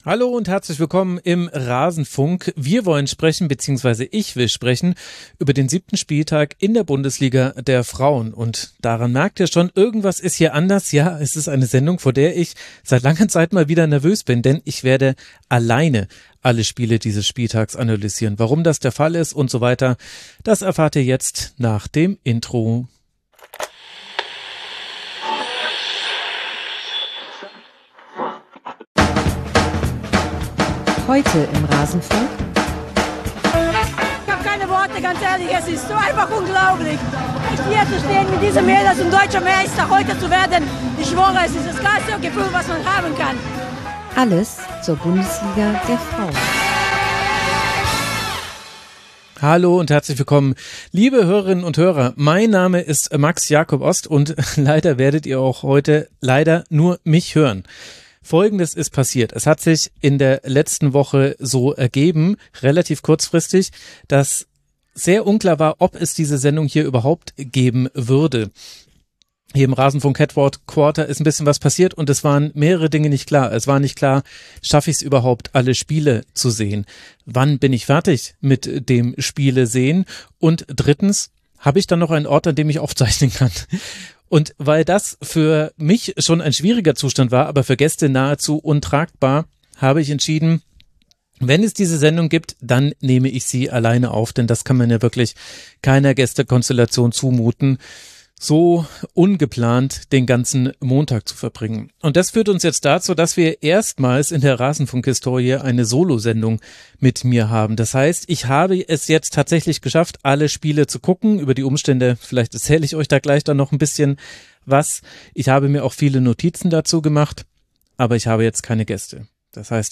Union schießt Leipzig ab, Bayern verliert schon wieder Spielerinnen, Leverkusen feiert alle Neune und der Club überpowert Freiburg. Wir analysieren den Spieltag – und zwar im Monolog.